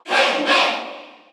File:Pac-Man Cheer Korean SSBU.ogg
Category: Crowd cheers (SSBU) You cannot overwrite this file.
Pac-Man_Cheer_Korean_SSBU.ogg.mp3